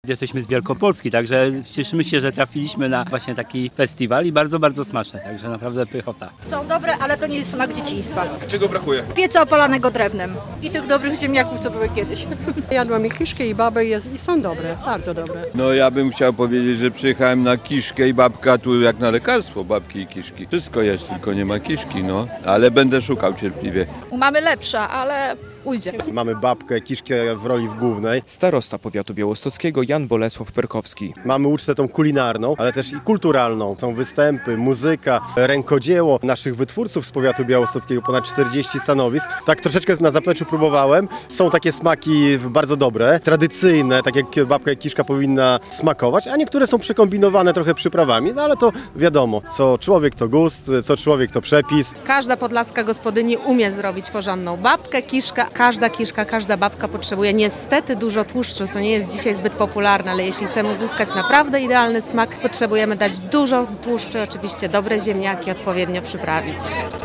W sobotę (3.08) pod amfiteatrem w Supraślu jak co roku zagościły tłumy.
Jak smakują mistrzowskie babki i kiszki - relacja